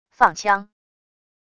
放枪wav音频